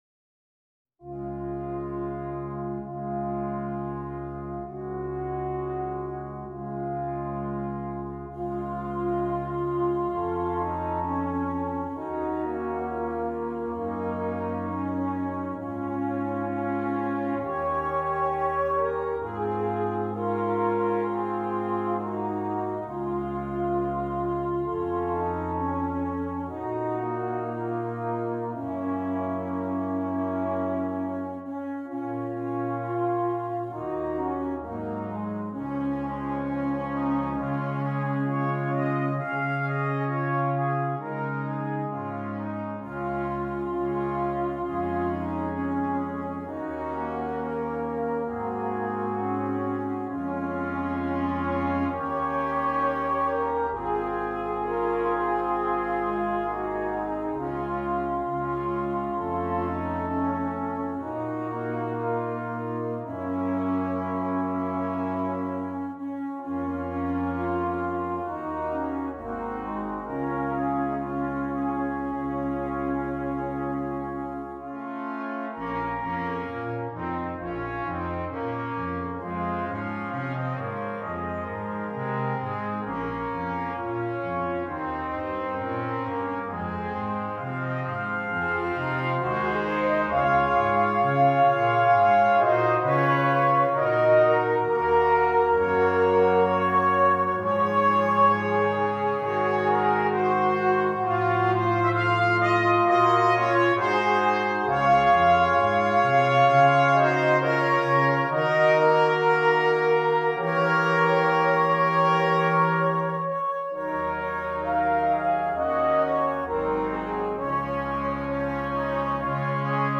Brass Quintet
Traditional